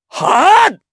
Lucias-Vox_Attack4_jp.wav